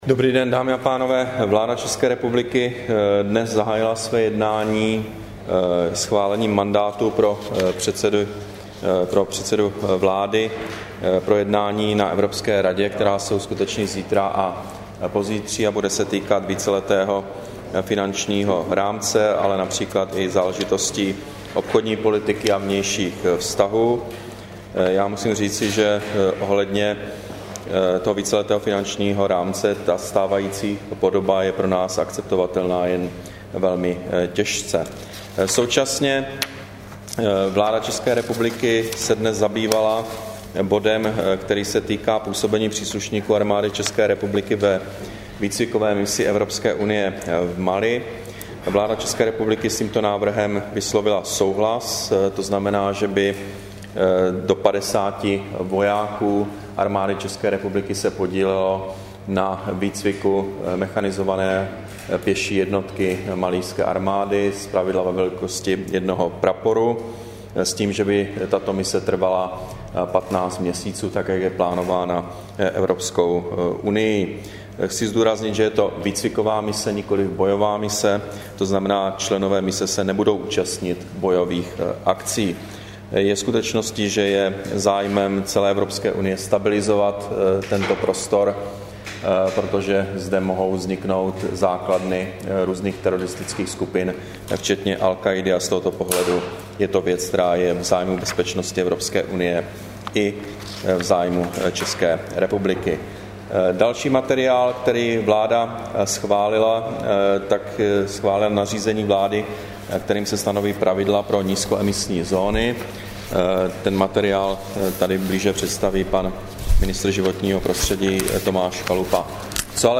Tisková konference po jednání vlády, 6. února 2013